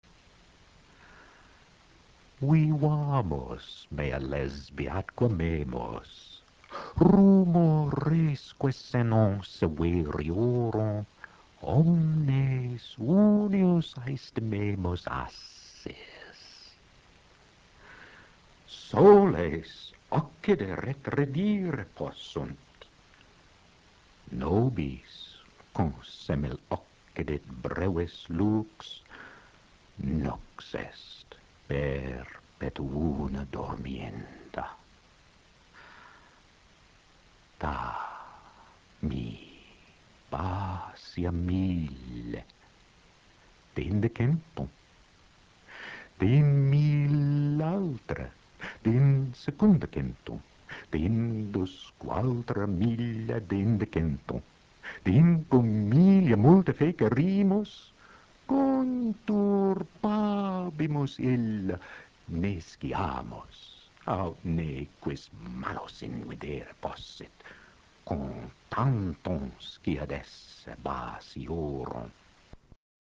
– faire écouter aux élèves le texte dit en latin restitué (le site de la Society for the oral reading of greek and latin literature, nourri par des professeurs passionnés de l’Université du Minnesota, met à disposition les lectures en prononciation restituée de quelques textes latins et grecs) ;